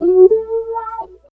70 GTR 6  -L.wav